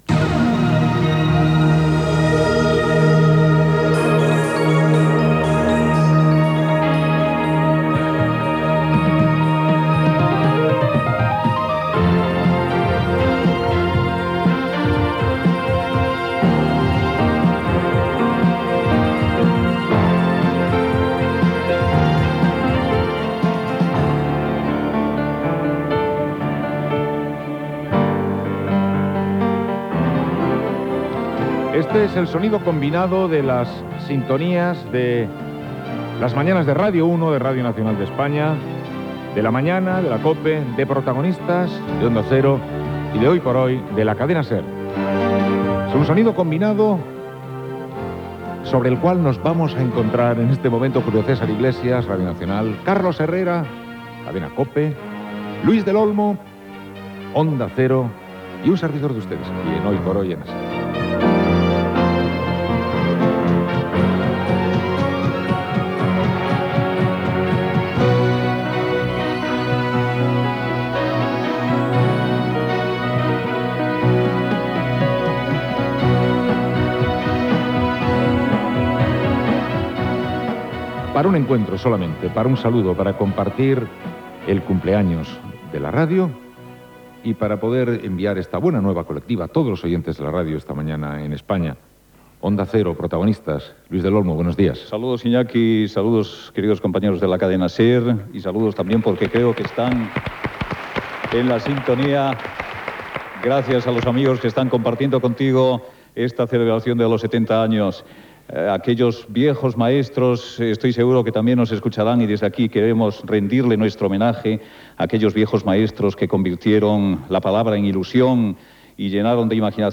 f23bbd3fad65c465c72933a67799eb474c2f128e.mp3 Títol Cadena SER Emissora Ràdio Barcelona Cadena SER Titularitat Privada estatal Nom programa Hoy por hoy Descripció Amb motiu del 70 aniversari de Ràdio Barcelona, les quatre cadenes espanyoles fan connexió conjunta.
Records radiofònics de tots aquests professionals. Missatge del rei d'Espanya Juan Carlos I llegit per Joaquín Soler Serrano
Info-entreteniment